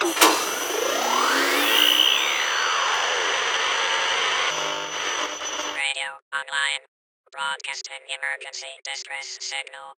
RadioBootupCombined.ogg